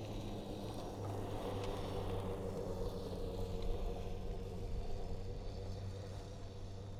Zero Emission Subjective Noise Event Audio File (WAV)
Zero Emission Snowmobile Description Form (PDF)